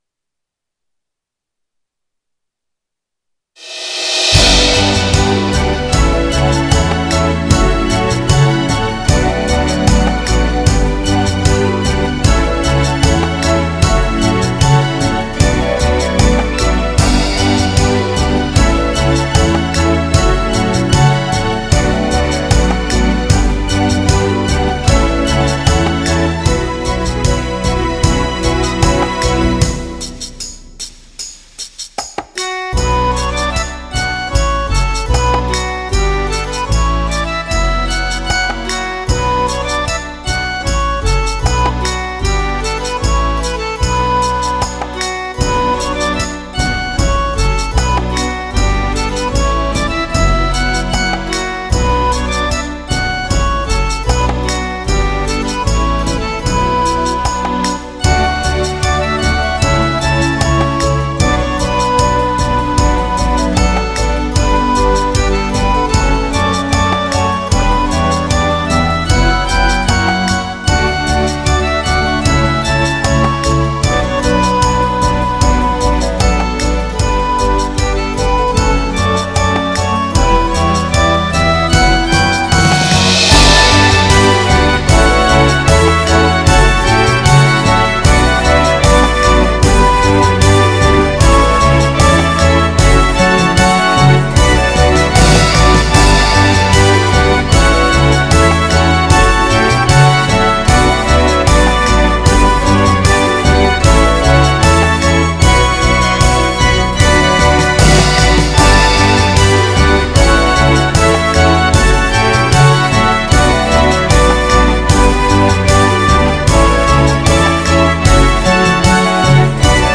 ドラマウテーマとい、ロ短調の呪縛が凄い事に。
あの調の孤独感と孤高な感じがたまらんのです。